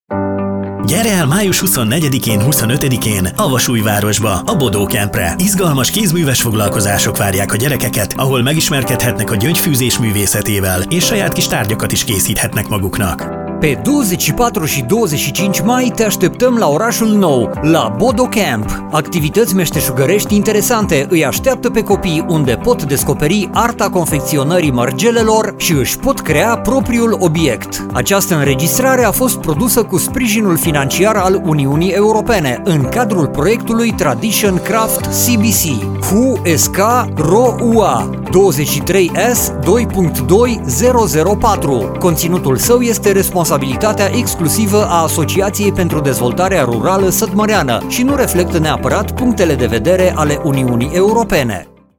Radio spot - Atelier pentru copii - Kézműves műhely gyerekeknek